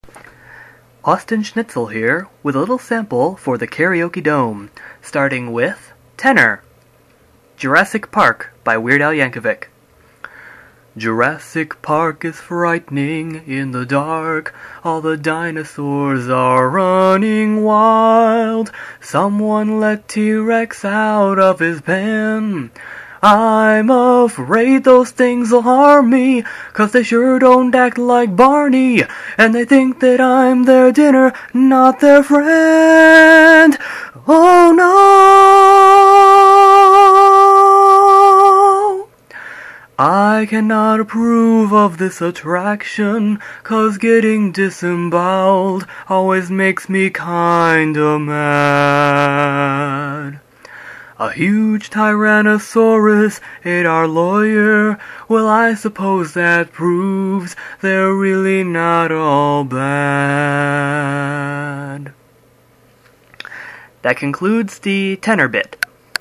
Tenor Sample